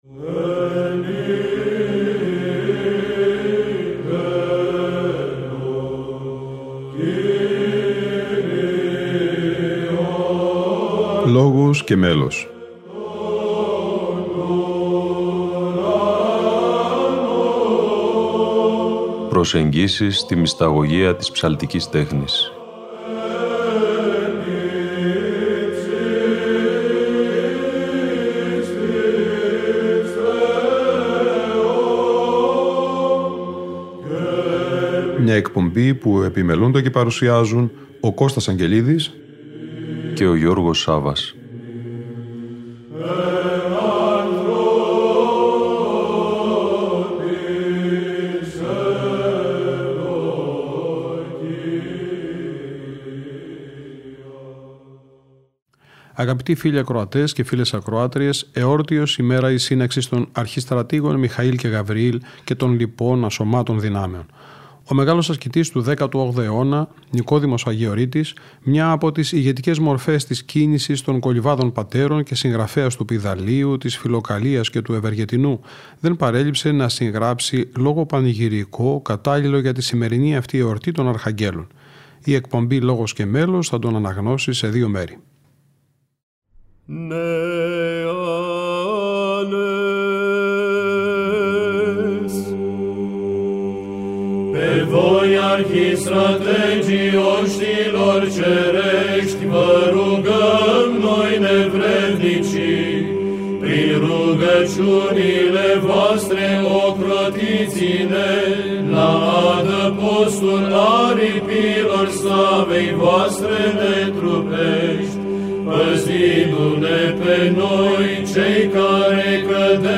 ΛΟΓΟΣ ΚΑΙ ΜΕΛΟΣ Λόγος εις τους Αρχαγγέλους Νικοδήμου Αγιορείτου - Μέλος Β.Χ. Σταυρουπόλεως (Α΄)